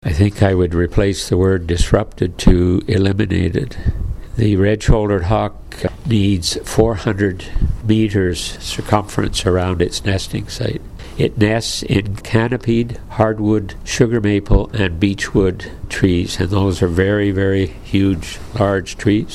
another member of the original Save the Grove Committee warned of just that when he spoke to elected representatives in April of 2018.